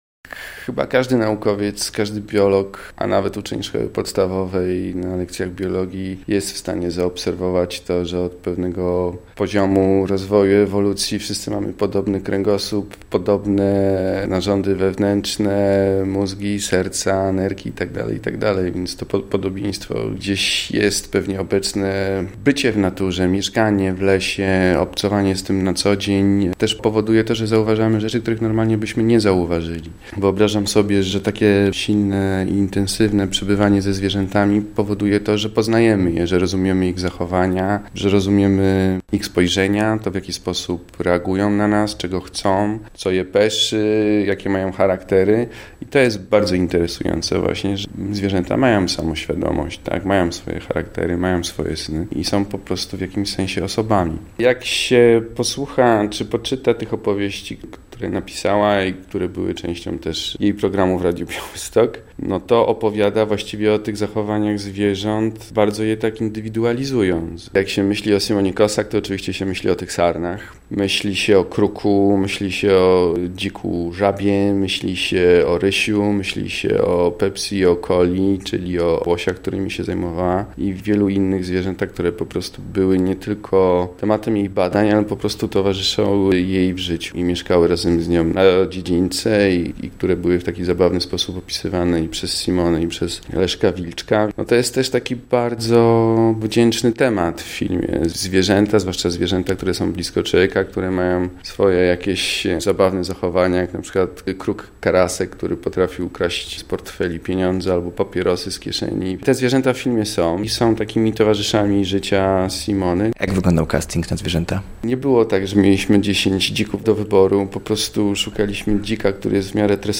Z reżyserem rozmawiamy o relacji człowieka i natury oraz o specyfice pracy ze zwierzęcymi aktorami. Adrian Panek mówi o tym, skąd inspiracja na zajęcie się tym tematem.